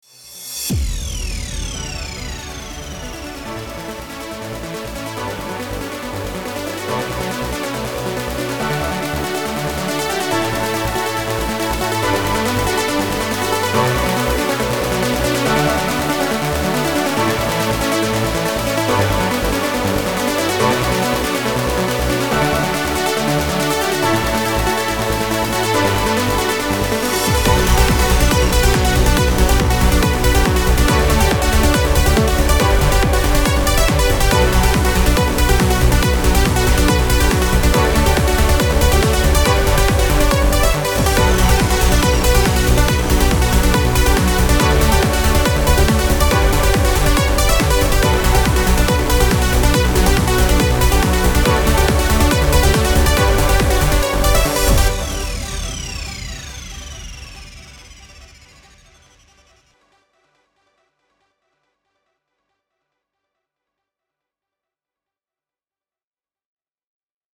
trance
progressive